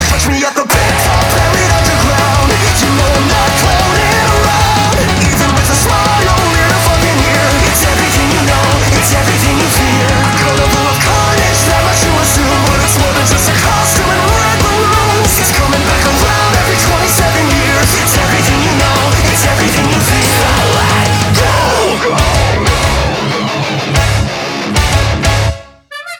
Драйвовые
hardcore
Metalcore
энергичные
быстрые
ska-punk